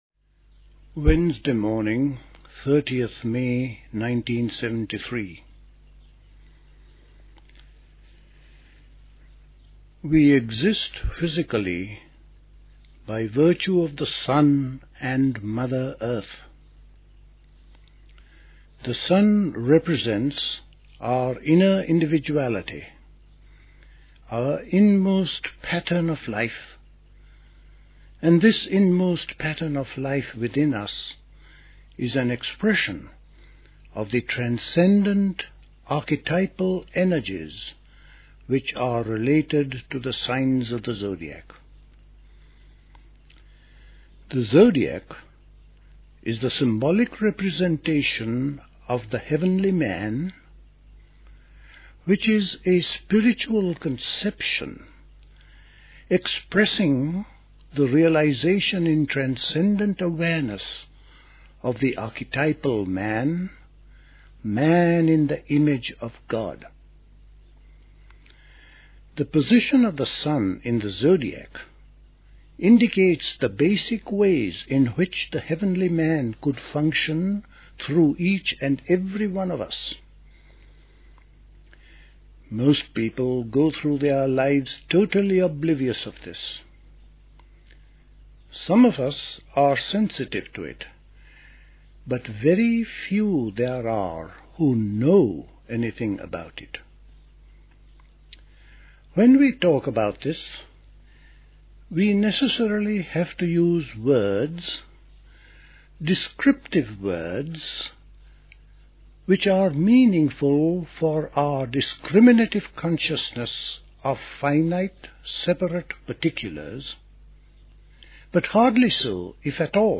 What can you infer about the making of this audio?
Recorded at the 1973 Catherington House Summer School.